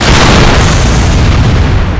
electrogun_hited.wav